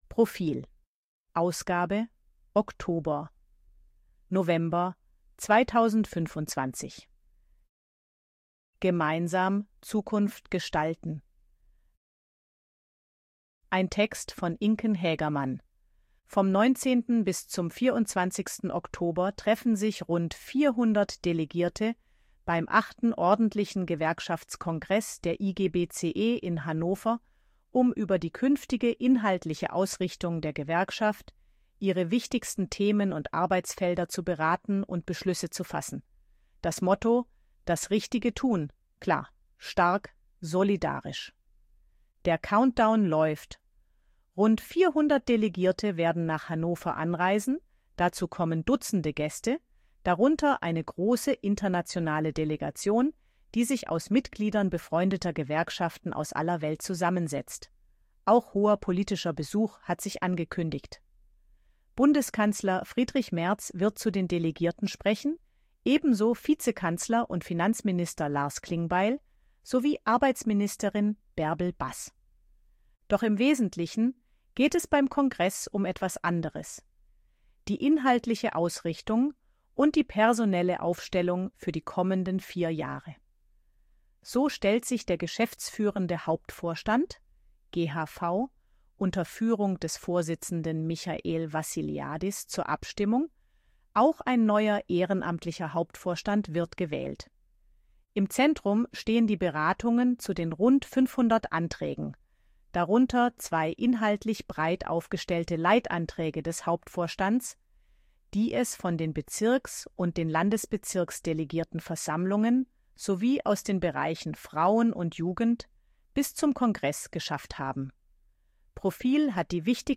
Artikel von KI vorlesen lassen ▶ Audio abspielen
ElevenLabs_255_KI_Stimme_Frau_HG-Story.ogg